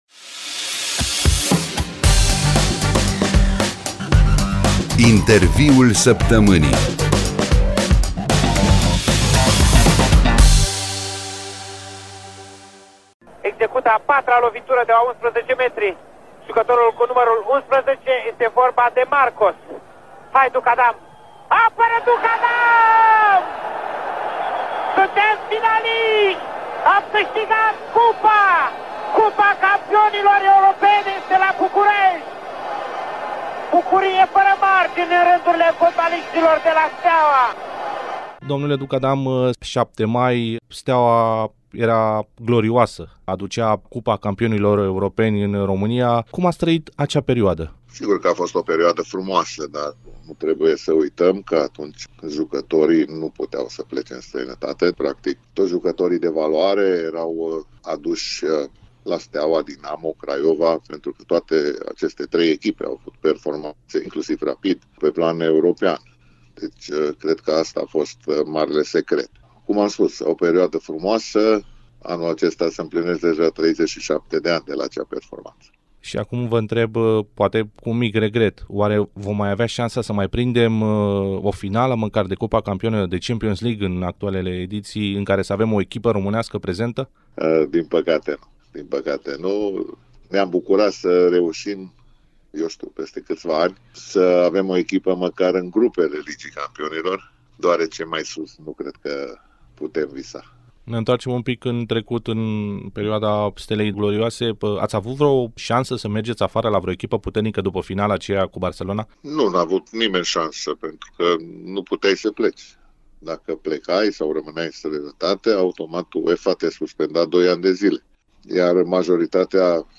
Home » Actualitatea militara » Interviul săptămânii » Helmut Duckadam: Important este să fim mulțumiți cu ceea ce avem, să avem o familie frumoasă lângă noi